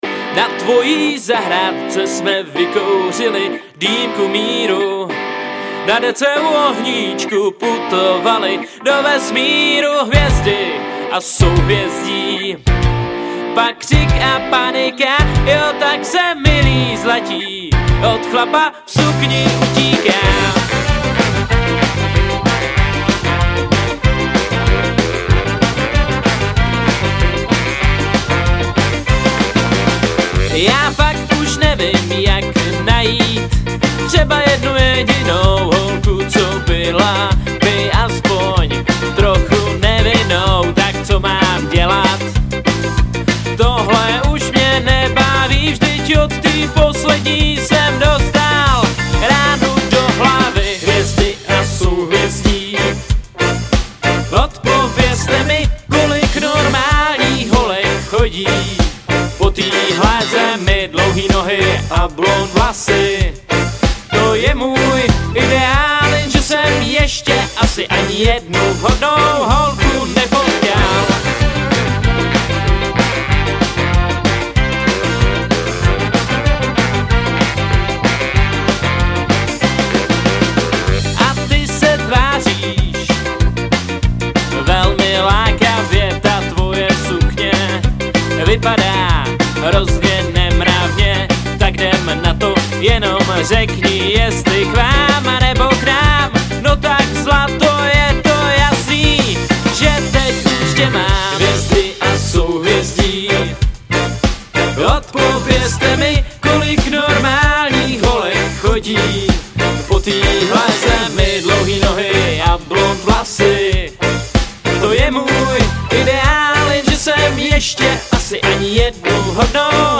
Žánr: Ska/Funk/Reggae